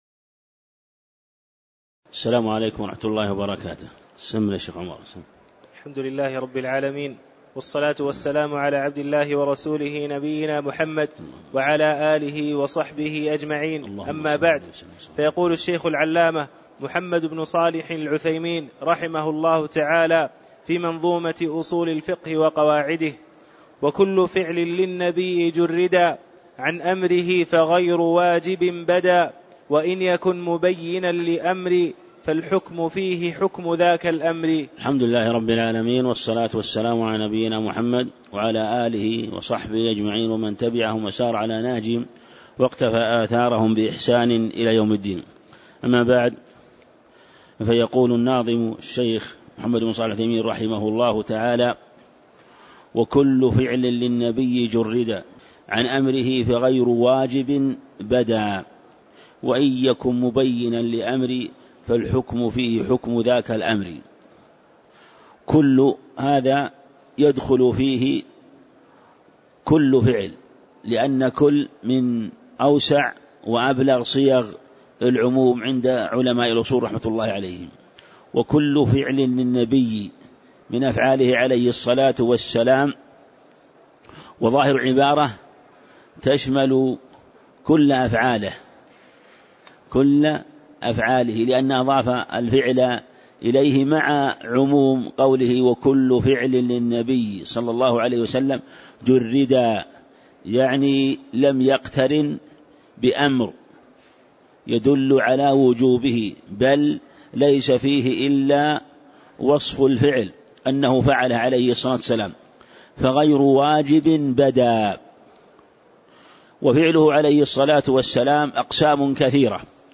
تاريخ النشر ٦ رجب ١٤٣٨ هـ المكان: المسجد النبوي الشيخ